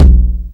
44_01_tom.wav